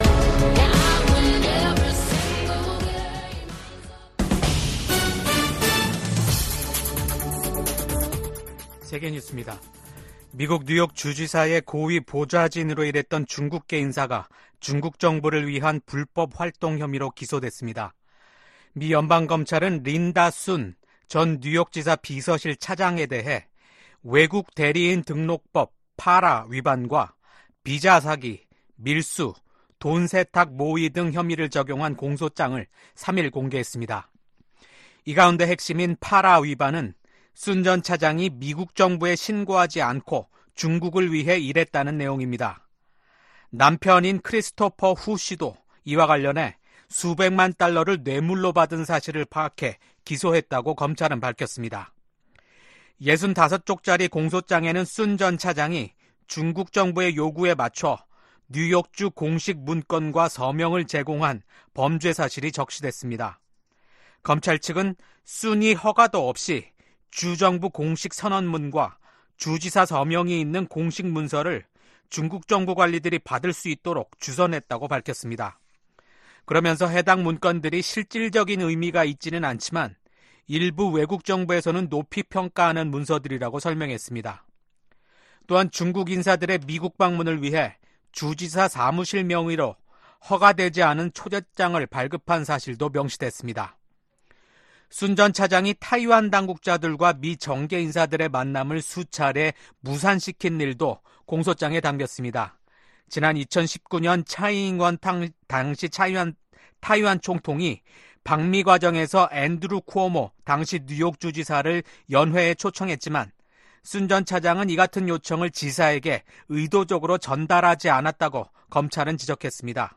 VOA 한국어 아침 뉴스 프로그램 '워싱턴 뉴스 광장' 2024년 9월 5일 방송입니다. 미국 국무부가 한국 정부의 대북 라디오 방송 지원 방침을 지지한다는 입장을 밝혔습니다. 기시다 후미오 일본 총리가 퇴임을 앞두고 한국을 방문해 윤석열 대통령과 회담을 합니다. 북한 열병식 훈련장 인근에 버스로 추정되는 차량 수십 대가 집결했습니다.